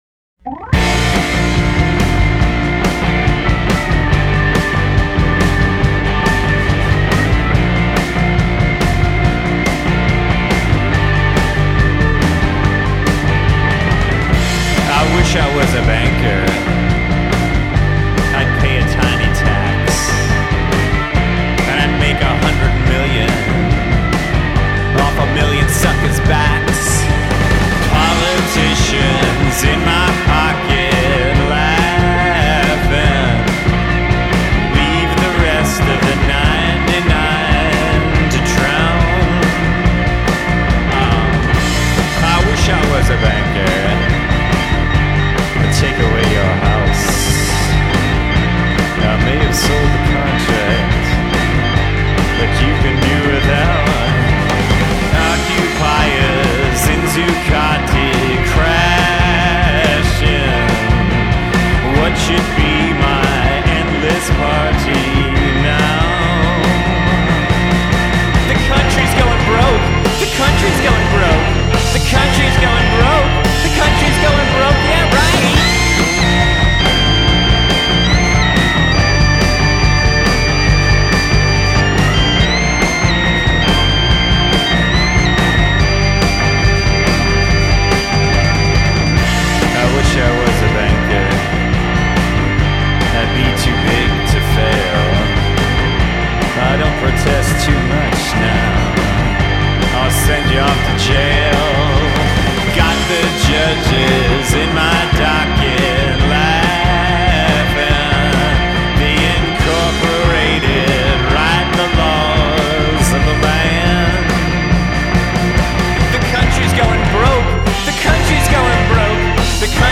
Brooklyn-based rock band
steely voice drips sarcasm